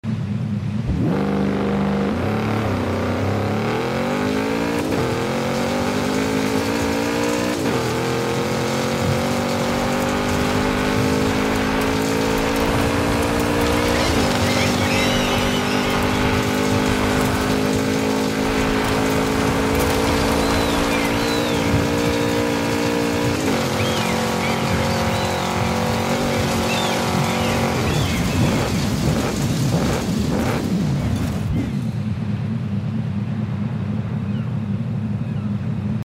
2019 Lamborghini Urus Off-Road Launch